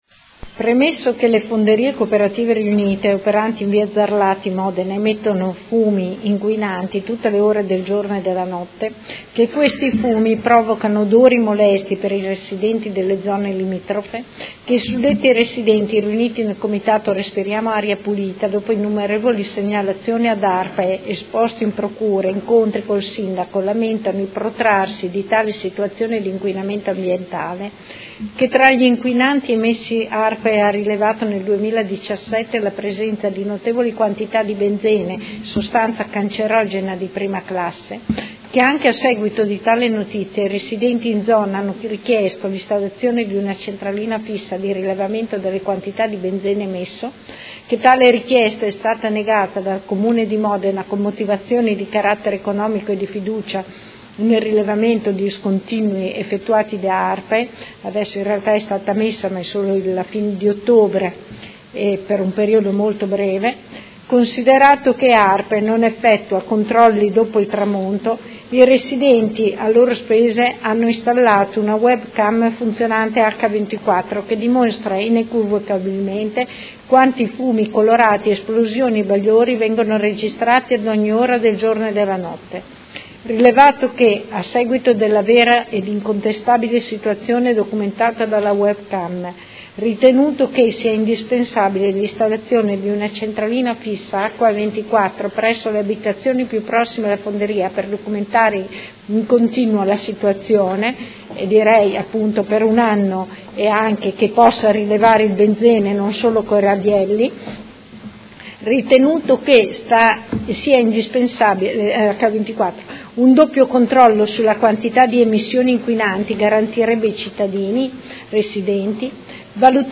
Seduta del 22/11/2018. Ordine del Giorno presentato dalla Consigliera Santoro (Lega Nord) avente per oggetto: Fonderie Cooperative Riunite, installazione di una centralina fissa di rilevamento emissioni inquinanti